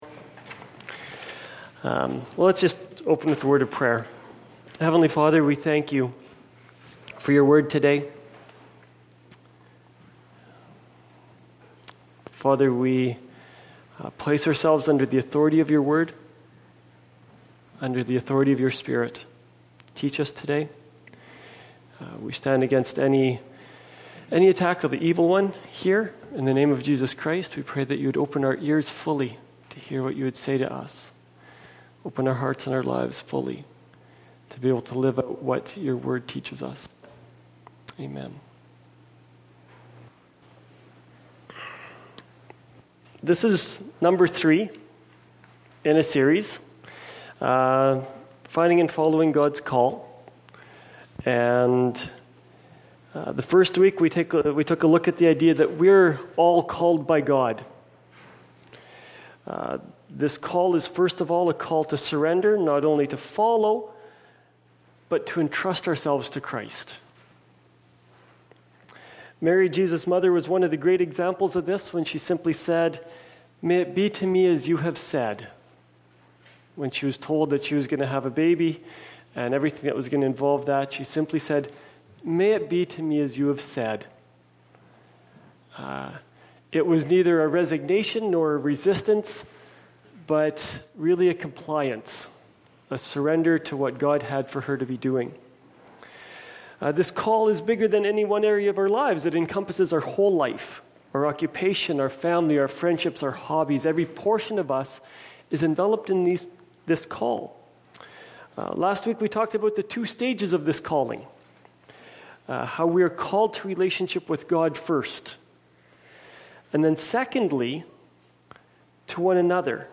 Finding and Following God’s Call: Sermon 3